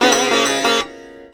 SITAR GRV 08.wav